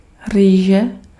Ääntäminen
IPA : /ɹaɪs/